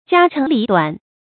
家常里短 jiā cháng lǐ duǎn 成语解释 犹“家长里短”。